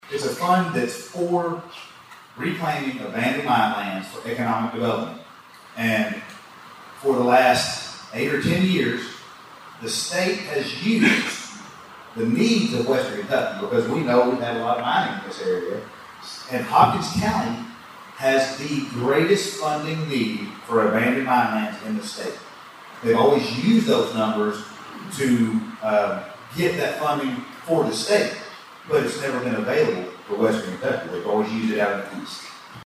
Local leaders are actively advocating in Frankfort and Washington, D.C., to secure funding for economic growth, veteran support, and infrastructure projects, which was one of the topics discussed at last week’s State of the Cities and County event.